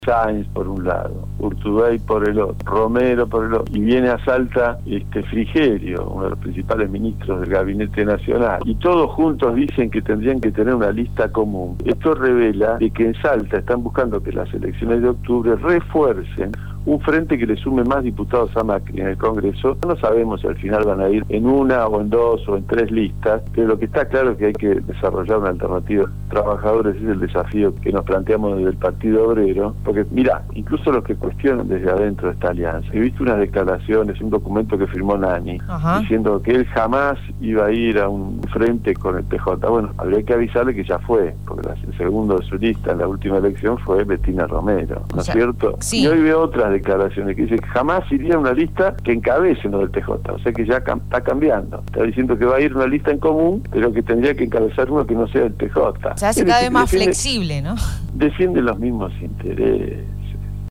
El Diputado Provincial Claudio Del Pla en diálogo con Radio Dinamo habló sobre las elecciones, sobre el frente electoral oficial que se armará en la provincia y criticó al dirigente radical Miguel Nanni.